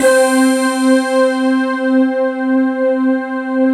PAD K-2000BR.wav